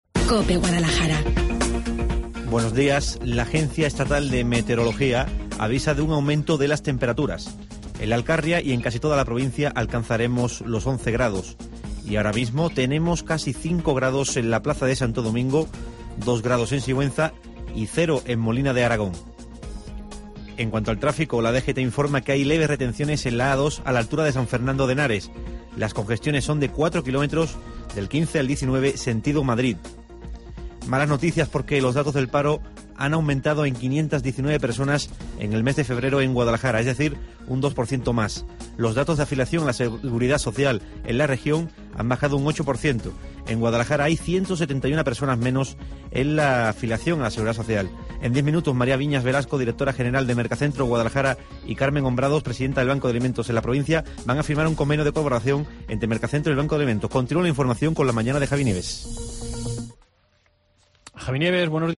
Informativo de servicio de Guadalajara 4 de MARZO